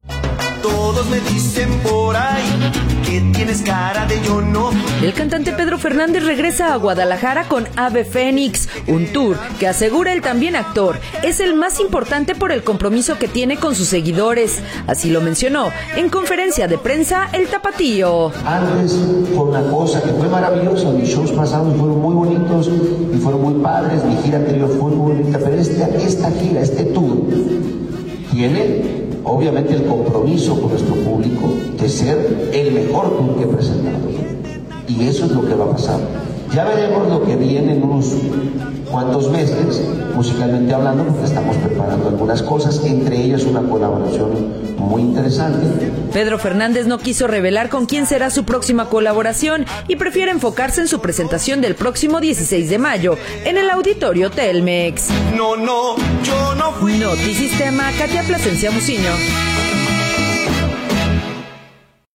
El cantante Pedro Fernández regresa a Guadalajara con “Ave Fénix”, un tour que asegura el también actor es el más importante por el compromiso que tiene con sus seguidores, así lo mencionó en conferencia de prensa el tapatío: “Antes fue una cosa […]